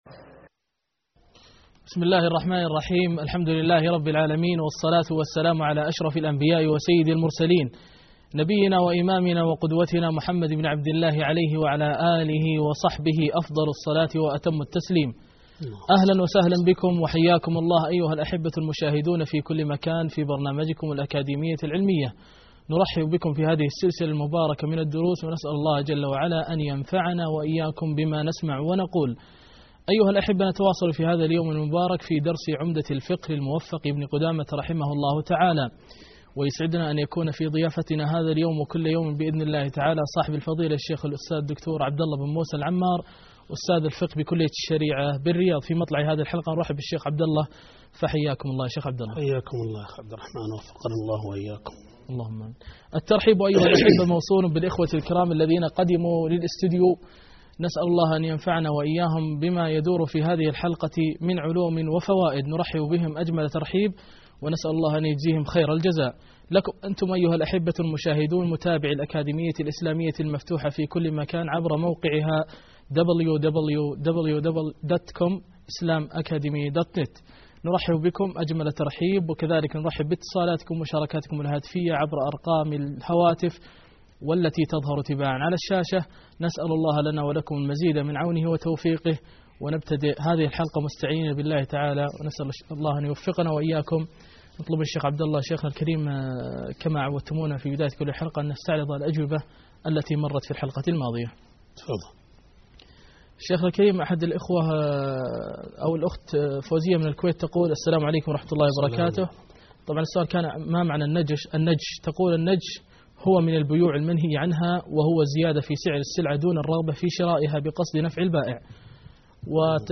الدرس 5 _ باب الربا